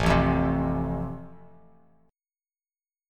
Am#5 chord